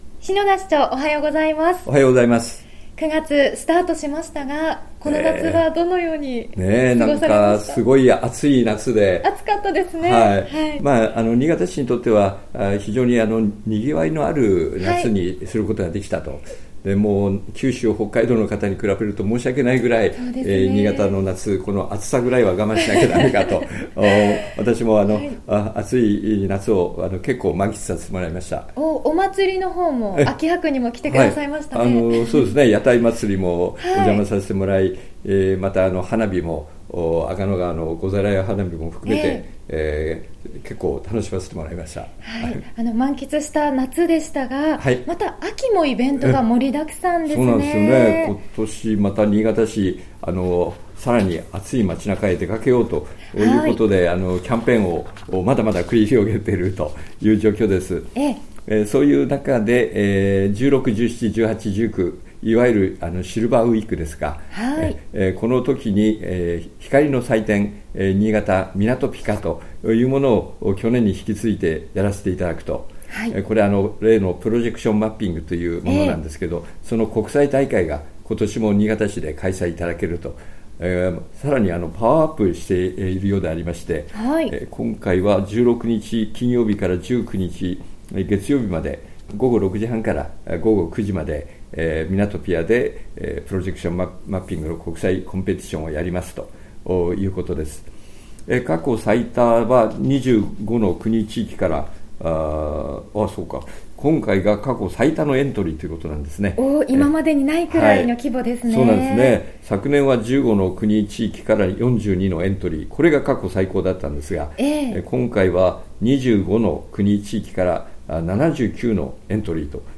２０１６年９月９日(金）放送分 | 篠田市長の青空トーク